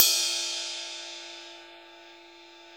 CYM RIDE504L.wav